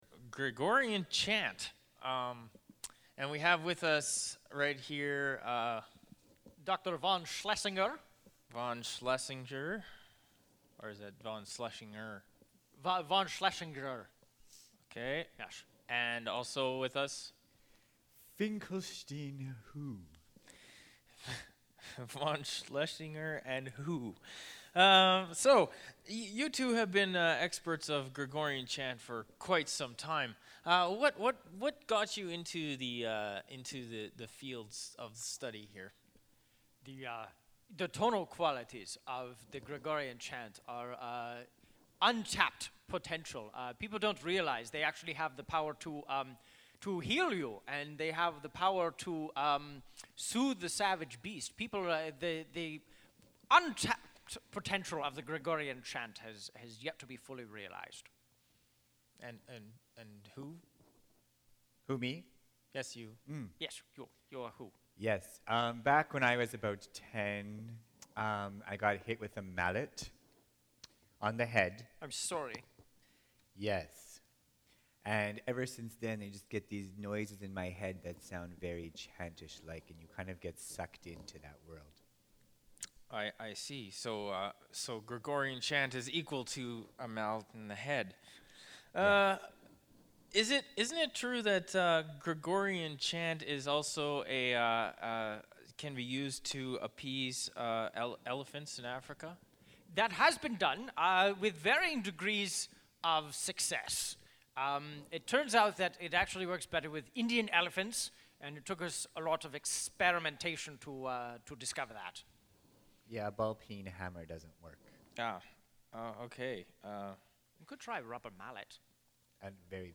Radio Demo:
A few years ago The Rascals were asked to do some recordings for a radio show.